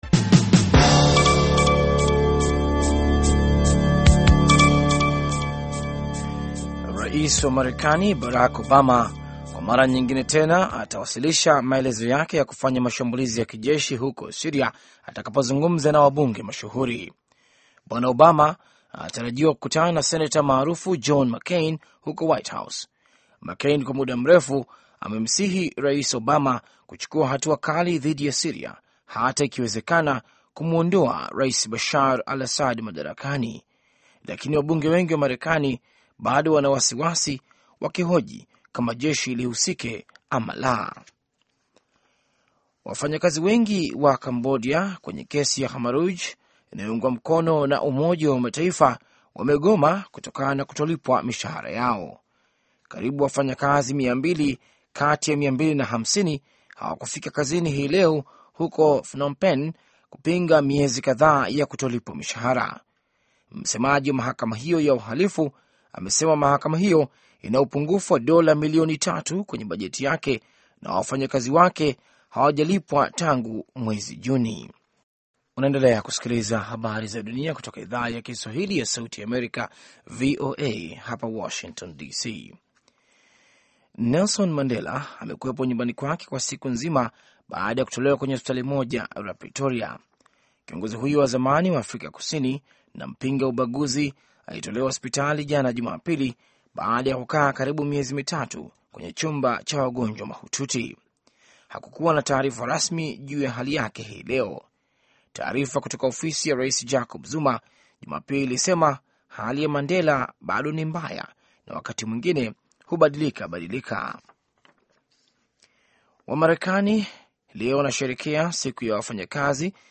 Taarifa ya Habari VOA Swahili - 6:08